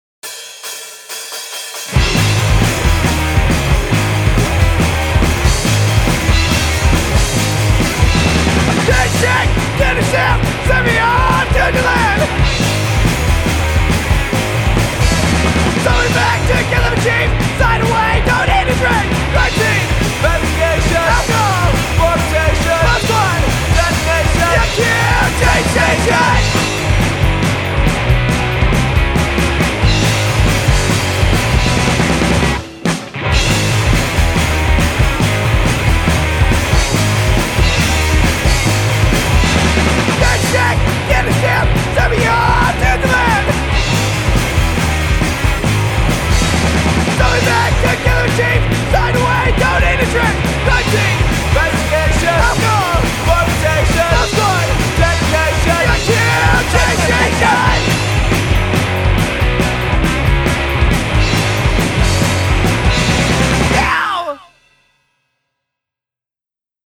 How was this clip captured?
Songs we’ve recently recorded and/or mixed.